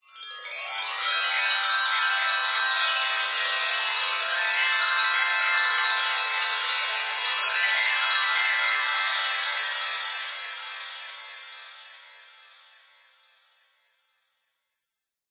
Звуки феи
Волшебная сверкающая пыль блестящая волшебная